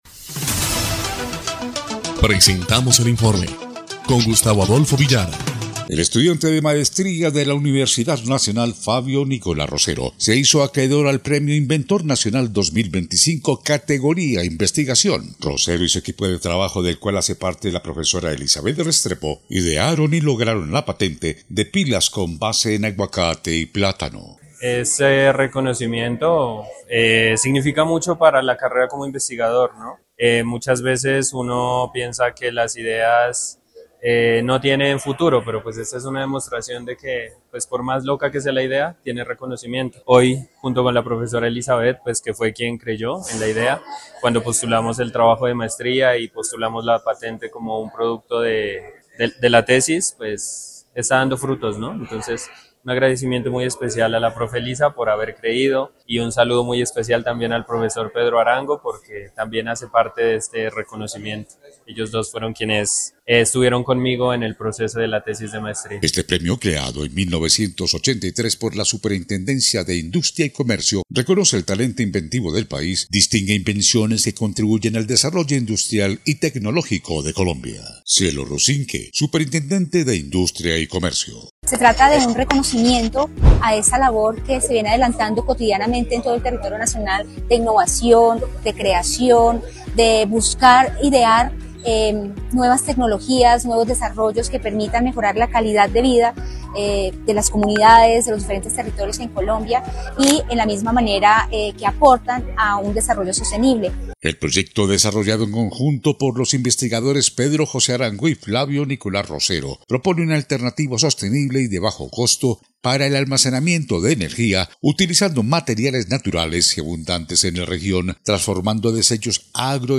EL INFORME 3° Clip de Noticias del 16 de septiembre de 2025